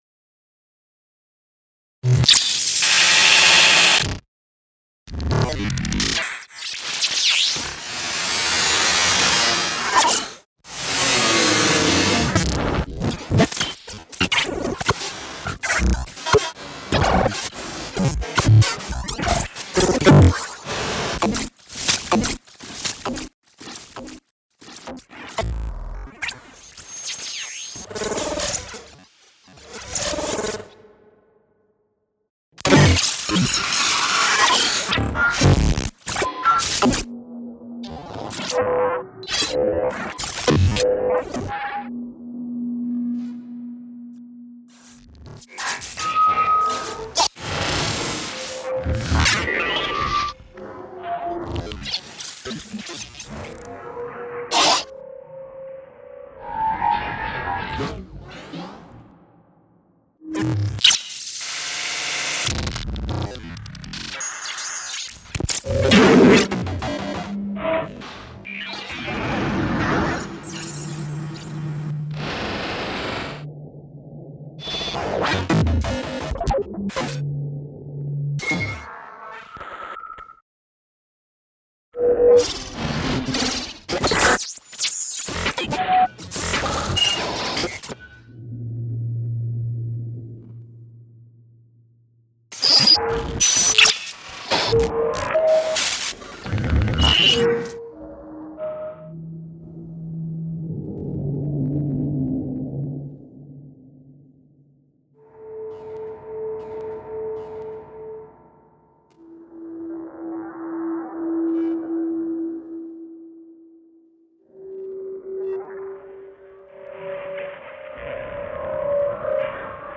Extreme sonic abstractions.